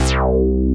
BIG REZ BASS.wav